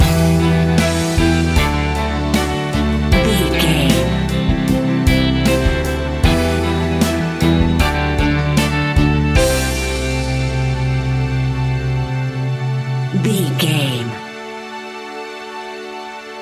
Pop Rock Anthem 15 Seconds.
Aeolian/Minor
pop rock instrumentals
bold
happy
peppy
upbeat
bright
bouncy
drums
bass guitar
electric guitar
keyboards
hammond organ
acoustic guitar
percussion